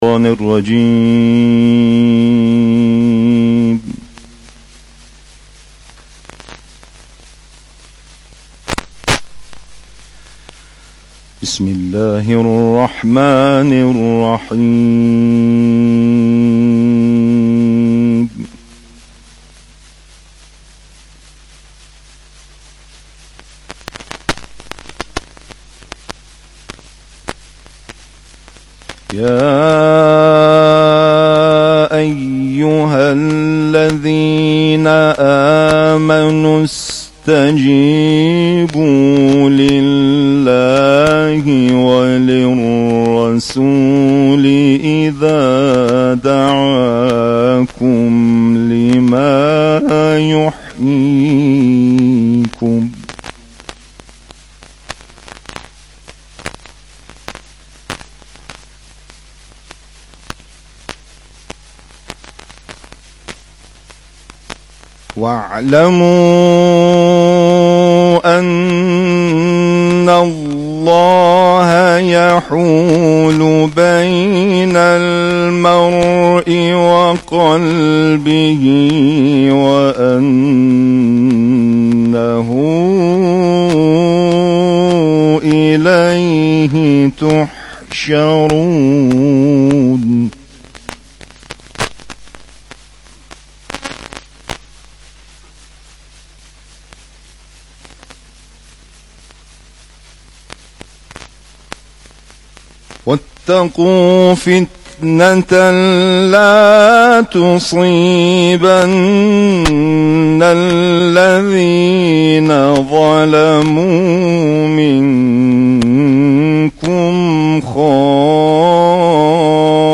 کرسی تلاوت اذانگاهی شورای عالی قرآن